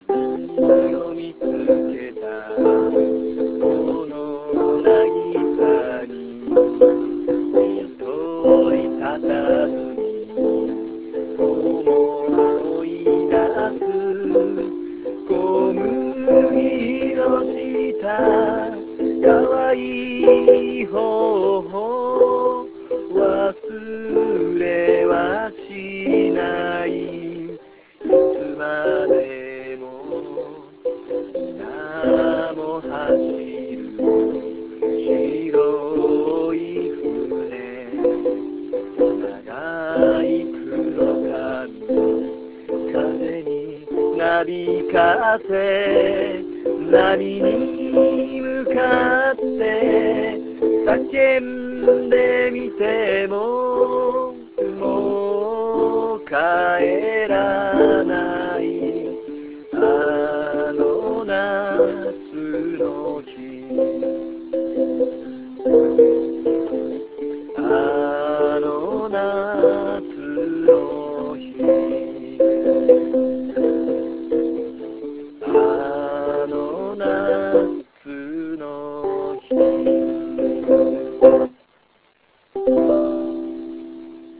Ukulele de song